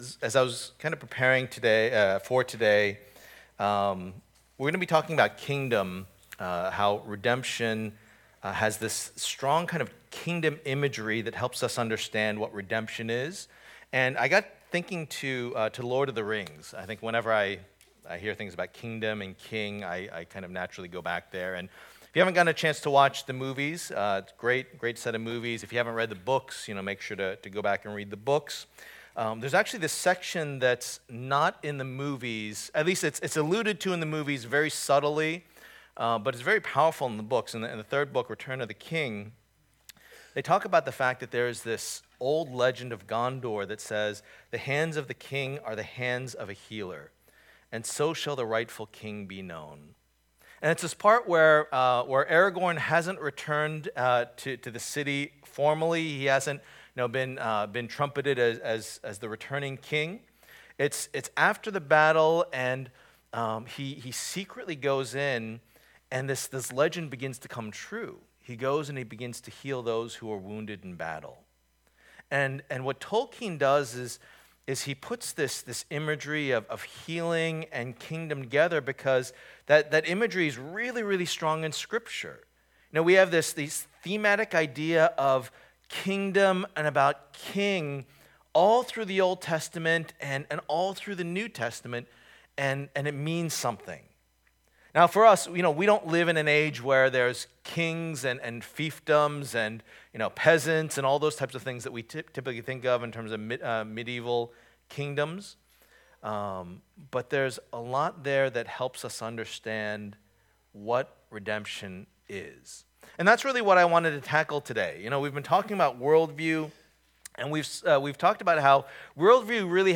Passage: Colossians 1:9-14 Service Type: Lord's Day